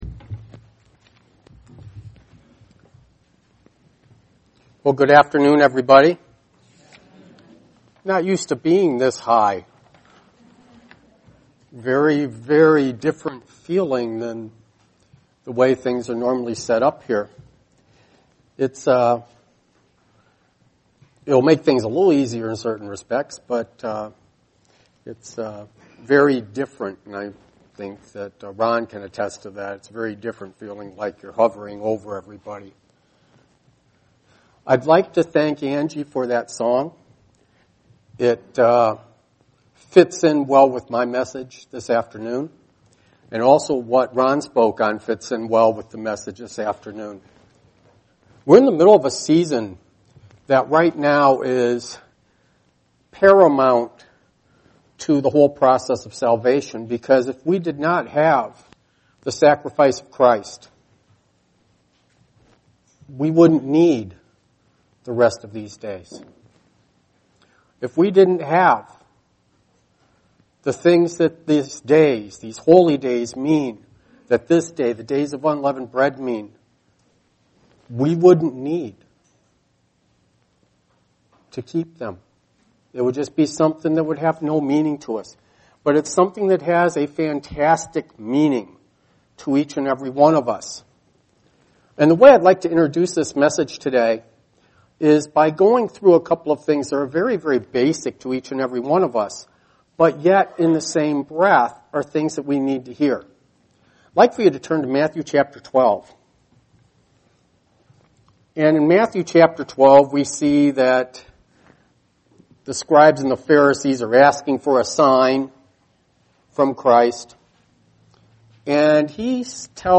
Jesus Christ's sacrifice, resurrection, and acceptance by the Father gives us the opportunity to be children of God. This message was given on the First Day of Unleavened Bread.